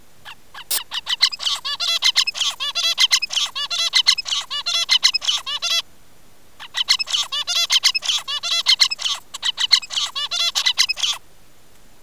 an audio clip of the bird’s song along with a short burst of white noise in the beginning.
Spectrogram of Zebra finch song along with white noise after 4th IN
proj_finch_wn.wav